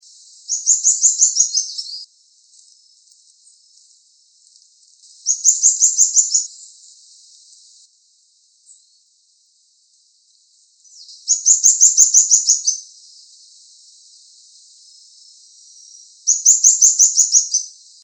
Bico-virado-miúdo (Xenops minutus)
Nome em Inglês: Atlantic Plain Xenops
Fase da vida: Adulto
Localidade ou área protegida: Reserva Privada y Ecolodge Surucuá
Condição: Selvagem
Certeza: Gravado Vocal